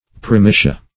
Search Result for " primitia" : The Collaborative International Dictionary of English v.0.48: Primitia \Pri*mi"ti*a\, n.; pl.
primitia.mp3